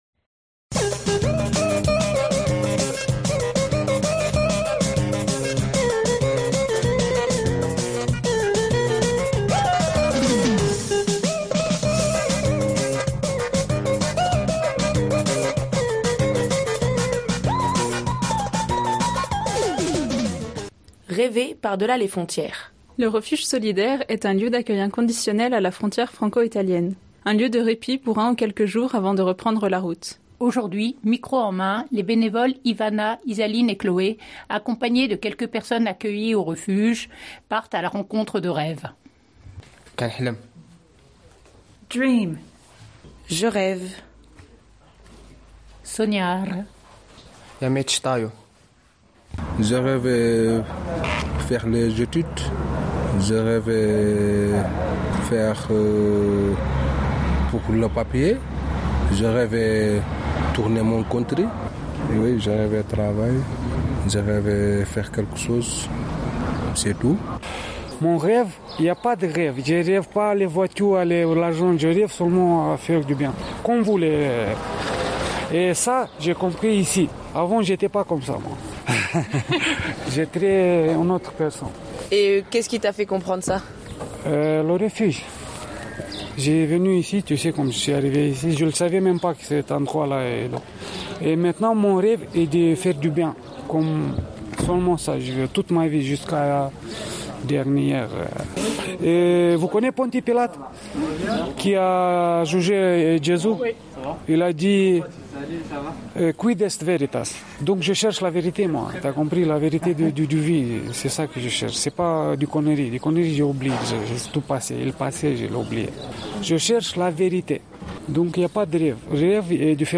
Rêver par delà les frontières.mp3 (5.28 Mo) Le Refuge Solidaire est un lieu d’accueil inconditionnel à la frontière franco-italienne, un lieu de répit pour un ou quelques jours avant de reprendre la route. Aujourd’hui micro en main, les bénévoles